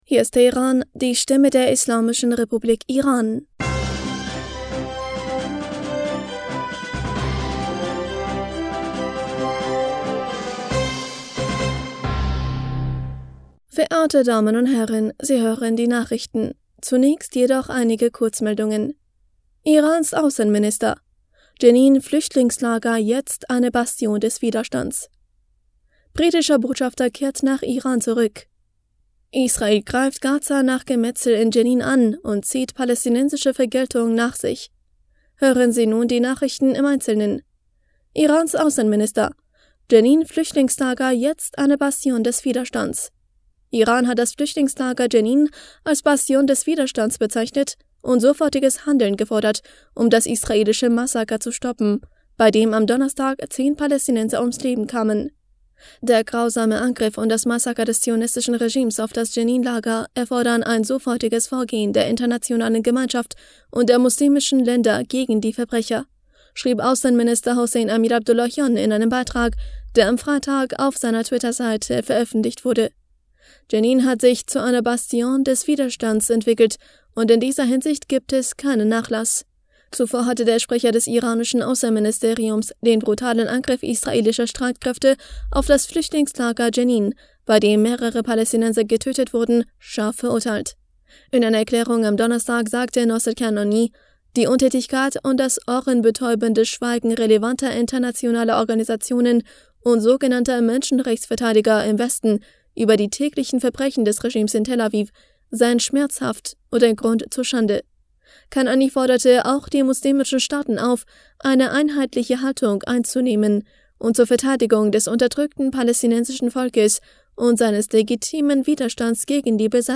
Nachrichten vom 27. Januar 2023